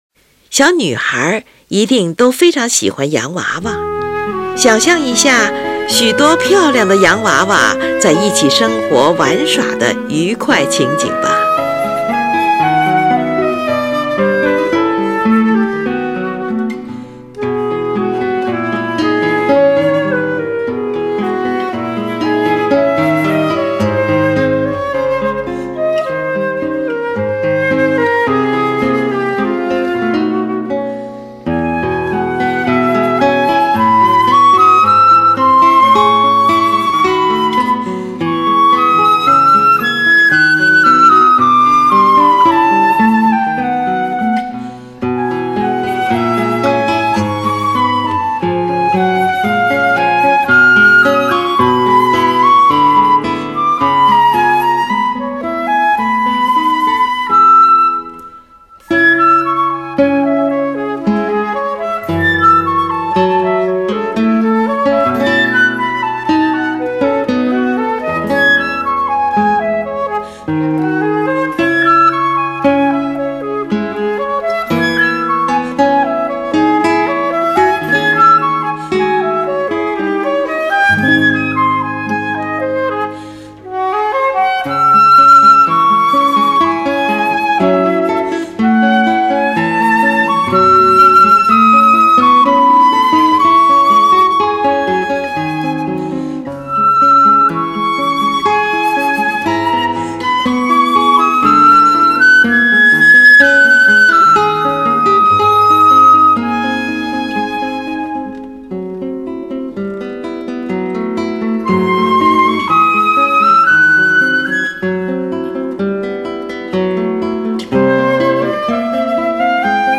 Suite for Flute and Guitar
这是一首由长笛与吉他合奏的乐曲，共分为四个乐章。